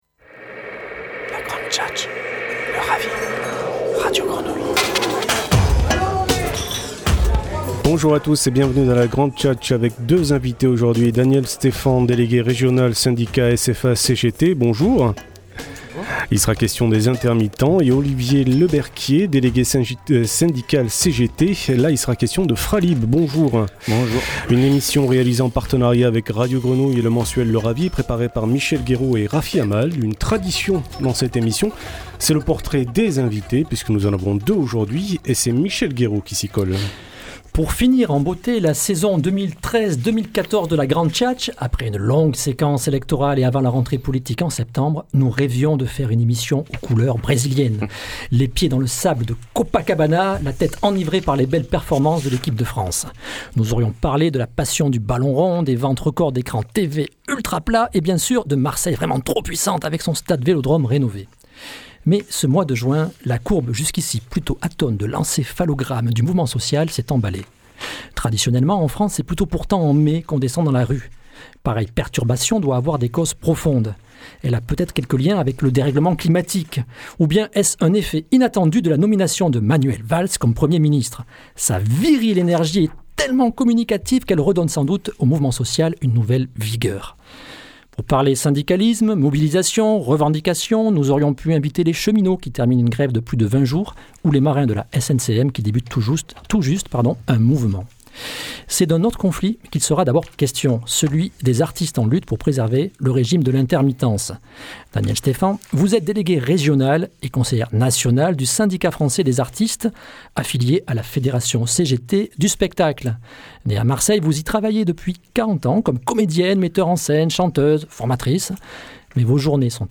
Entretien en partenariat avec Radio Grenouille